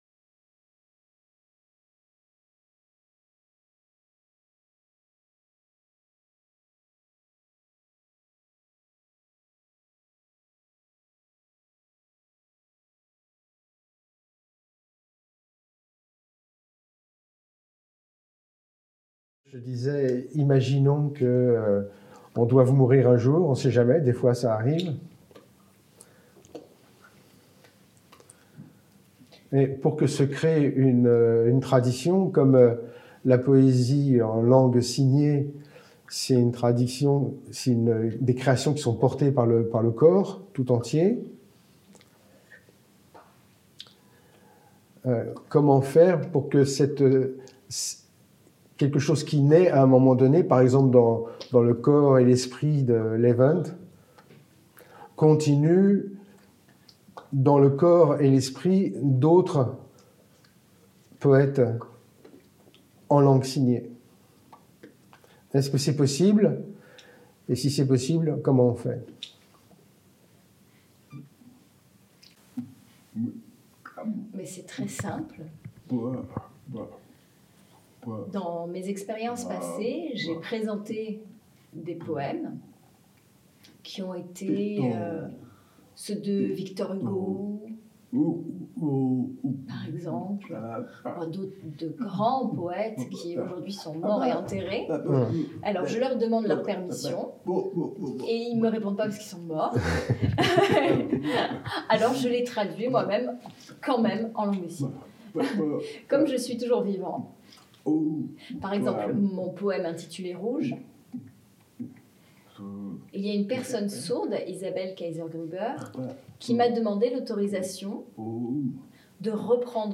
Voici un extrait de cet entretien…